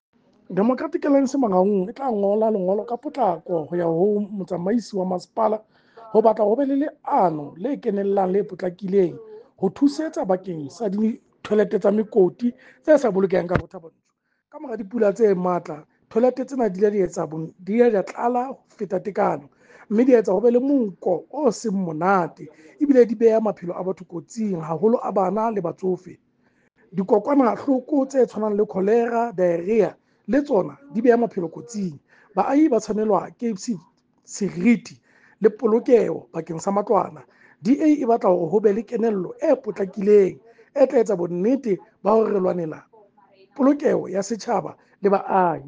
Sesotho soundbites by Cllr Kabelo Moreeng and